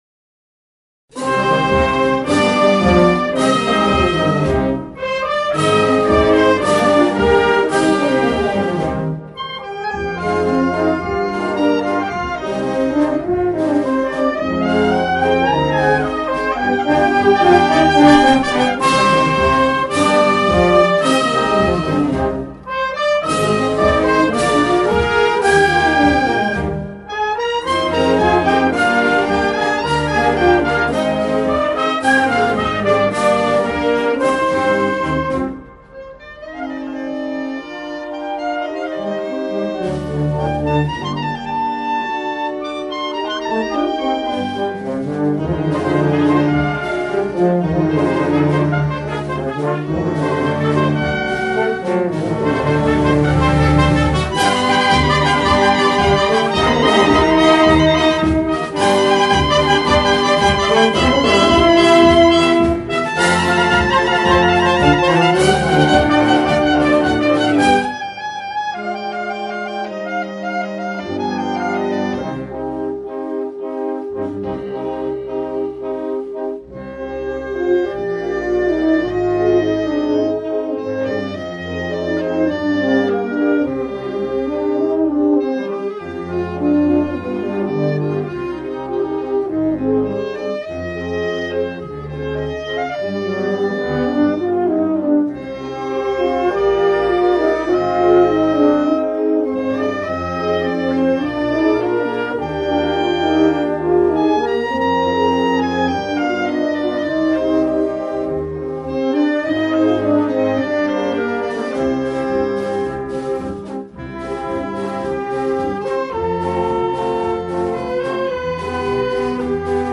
Marcia Sinfonica